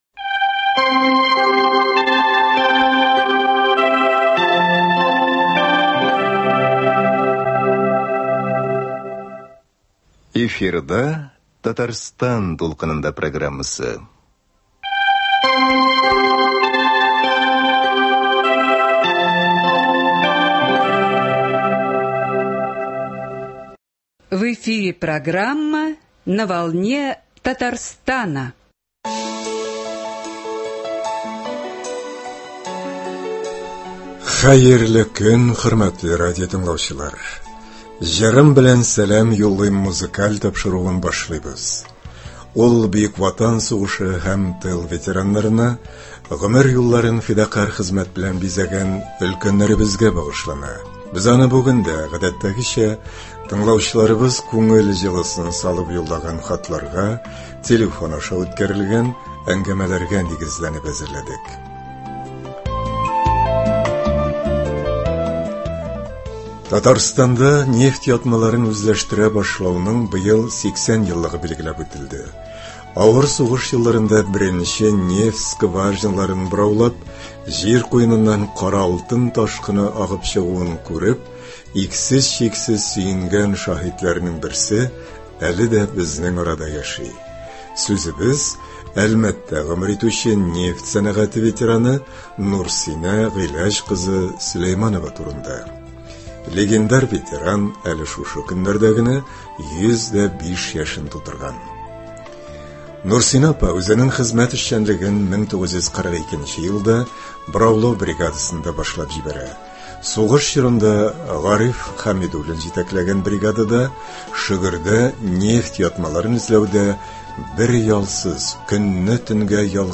Ветераннар өчен музыкаль программа.